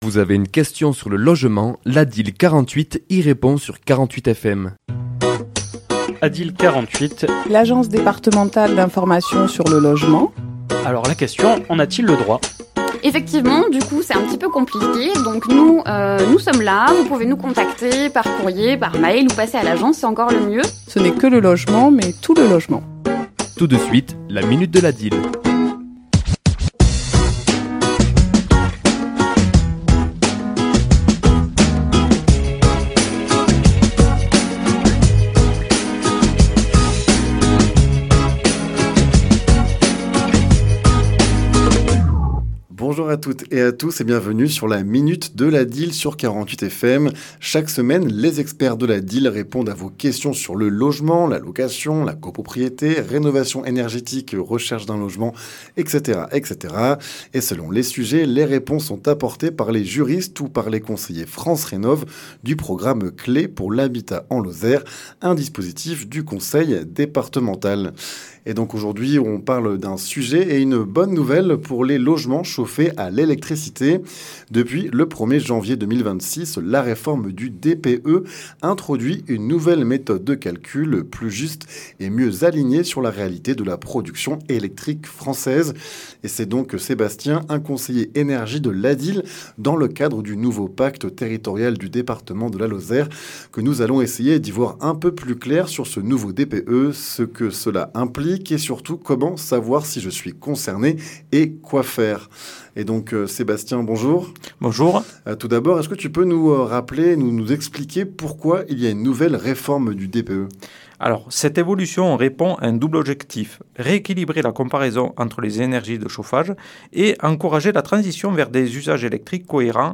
Chronique diffusée le mardi 10 mars à 11h et 17h10